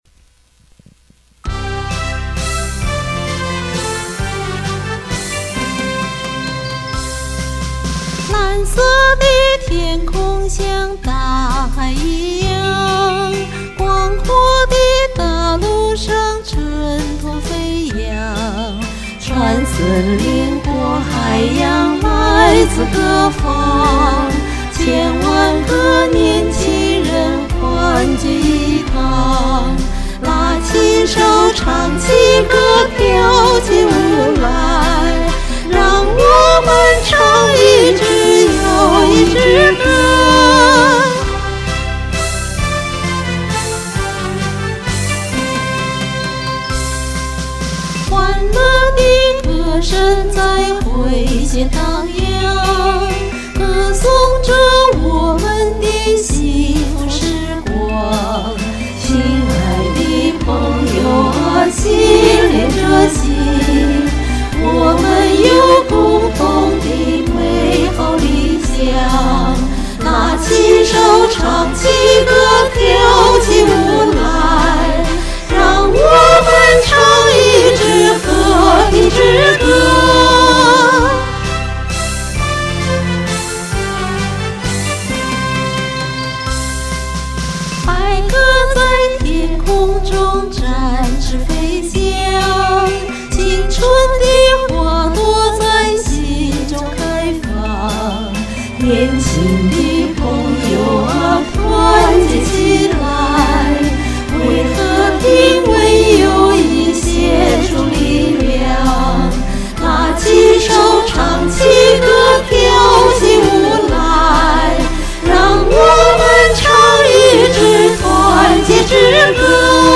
一首歡快的老歌
就試着錄了二聲部。
這首歌的合唱不僅有和聲，還有立體聲，賞心悅耳，效果一流。。